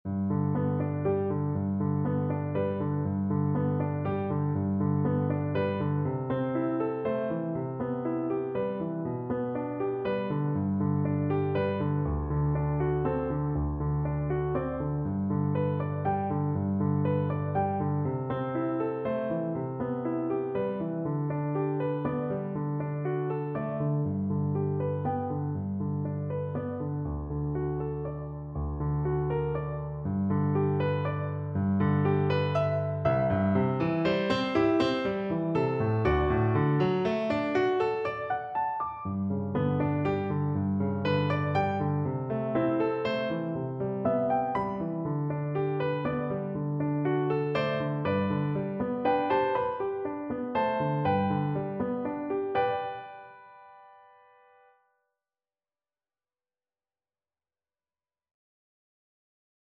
Free Sheet music for Soprano (Descant) Recorder
6/8 (View more 6/8 Music)
Andantino =c.120 (View more music marked Andantino)
Traditional (View more Traditional Recorder Music)